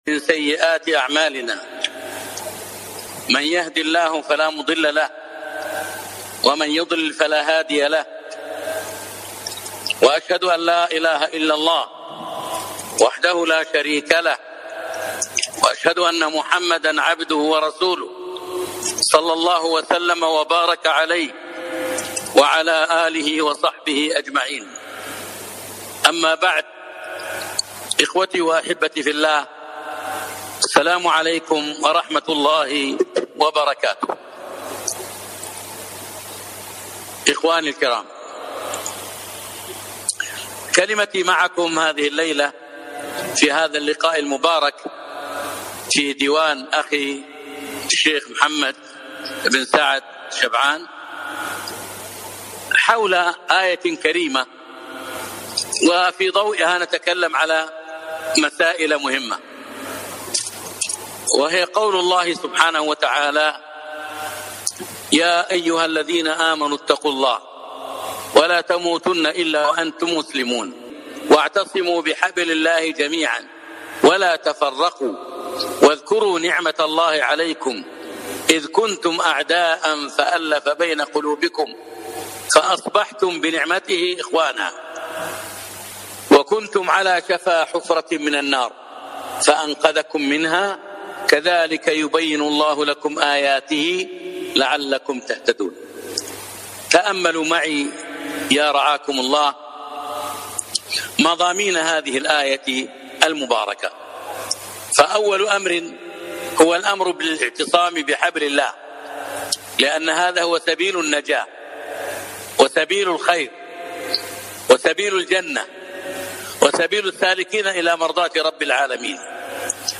كلمة - توجيهات في وقت الفتن